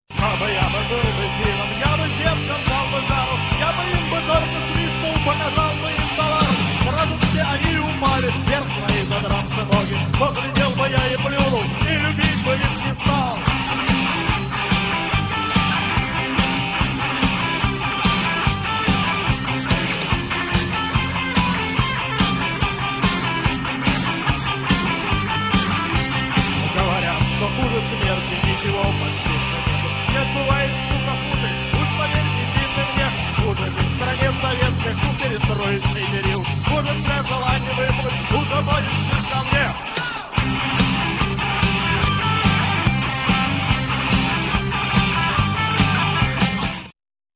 Гитарист
попытался соединить панк-рок и этнические мотивы